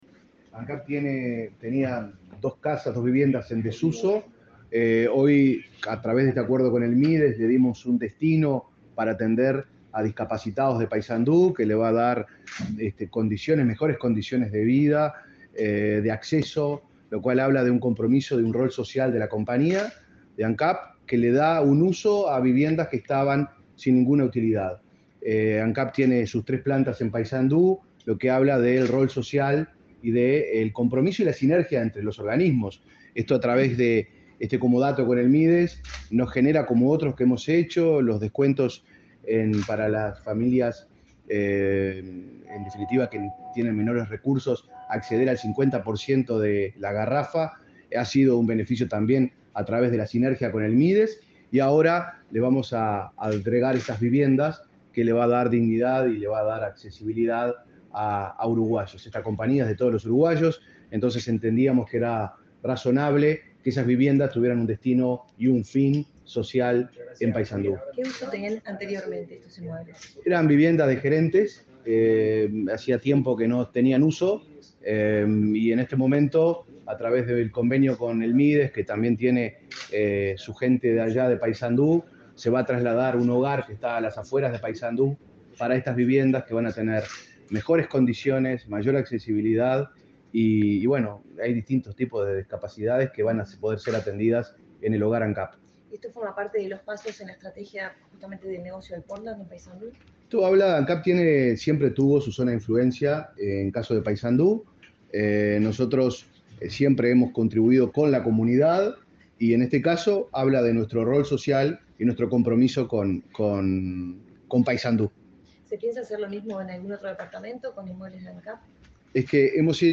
Declaraciones del vicepresidente de Ancap, Diego Durand